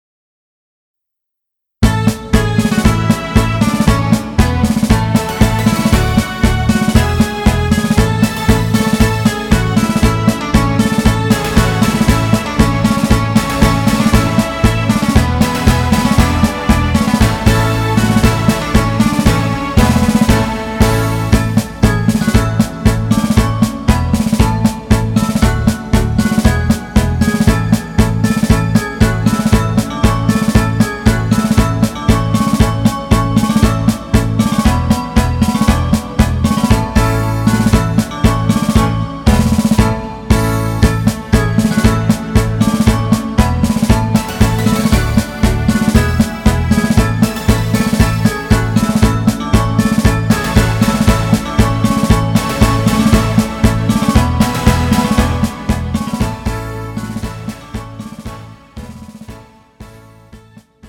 음정 A 키
장르 가요 구분 Pro MR